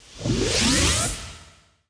newmeteor.wav